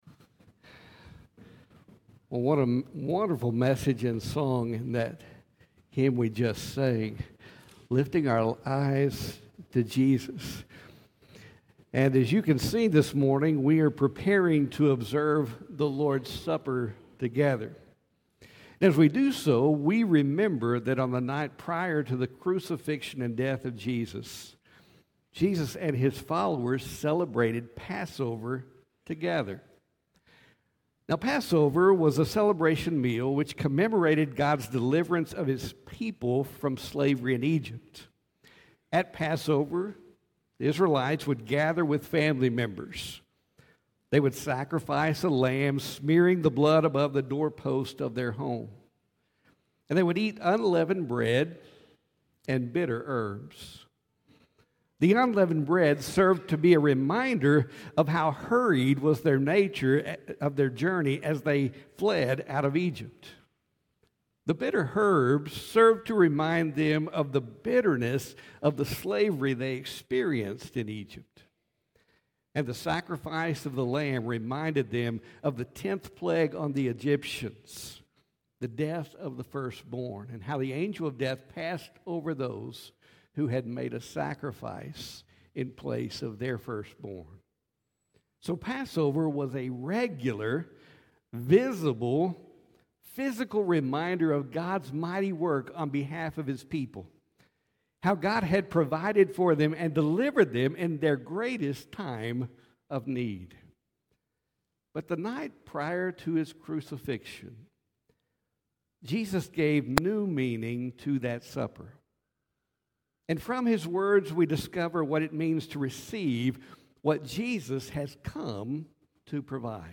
Sermons | First Baptist Church Brownwood